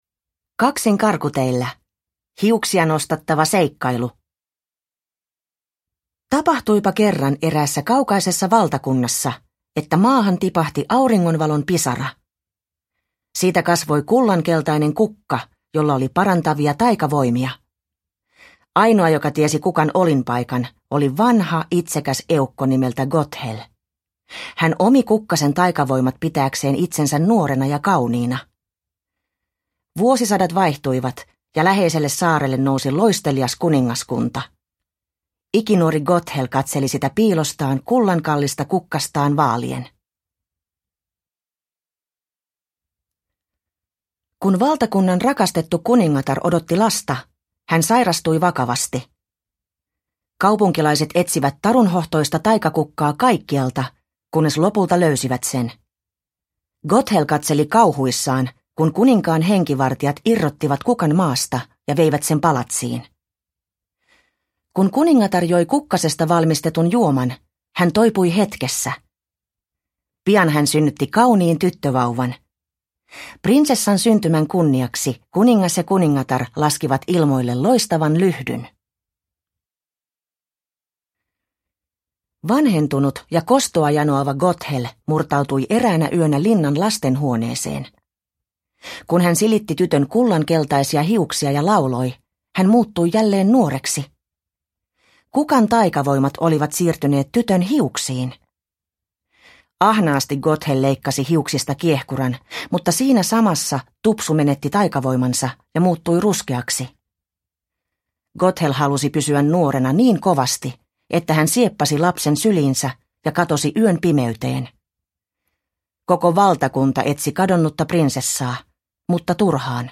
Kaksin karkuteillä – Ljudbok – Laddas ner